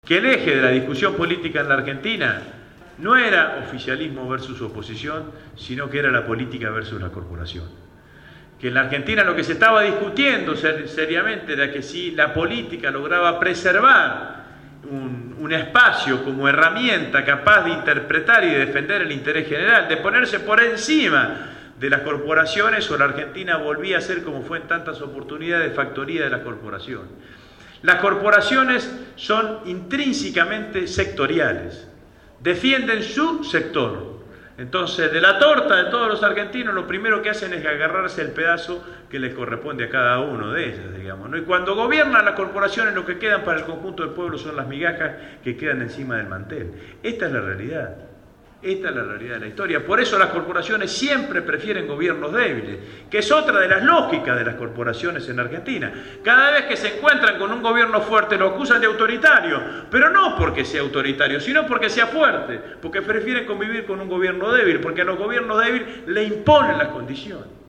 El último 4 de Octubre, organizado por la agrupación «Todas con Cristina«, estuvo presente en el espacio de la Radio Gráfica Agustín Rossi, Presidente del Bloque del FPV en la cámara baja.
Casi dos horas de charla-debate sirvieron para dejar satisfecha a la concurrencia, responder inquietudes y plantear nuevos desafios